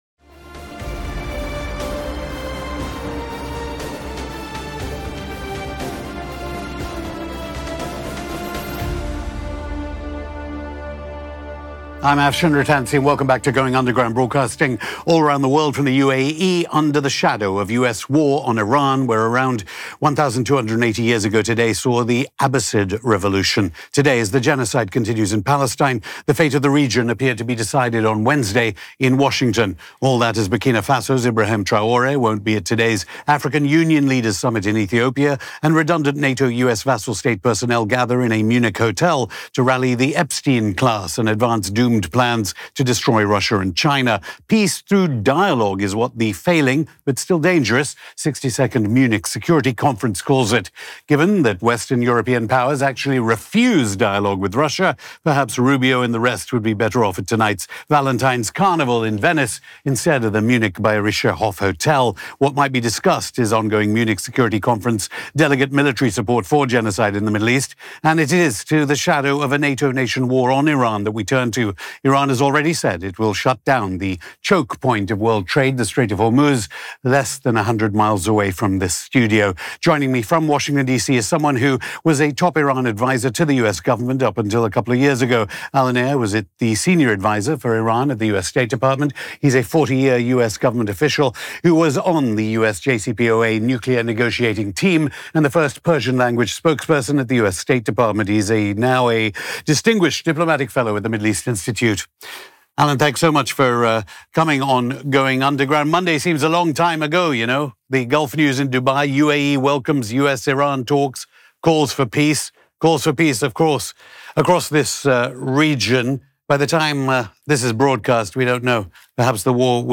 n this episode of Going Underground, we speak to Alan Eyre, Former Senior Advisor for Iran at the US State Department and the First Persian-Language Spokesperson at the US State Department.